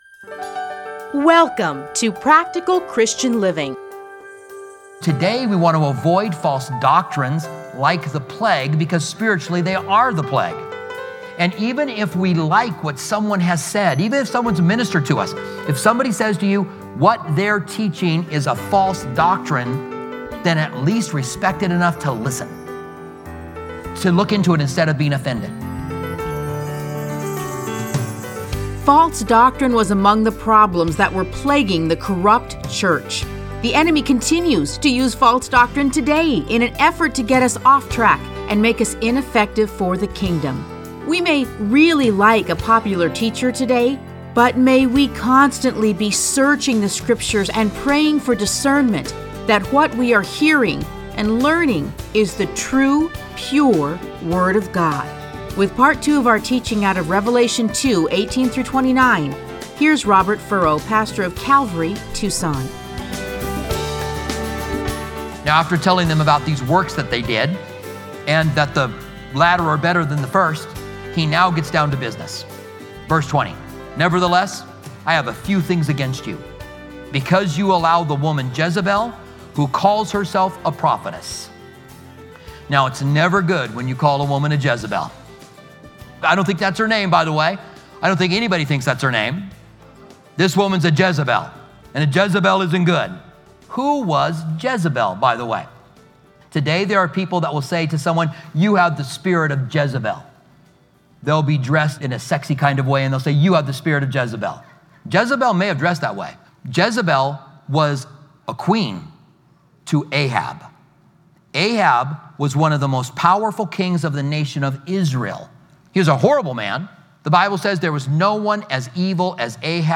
Listen to a teaching from Revelation 2:18-29.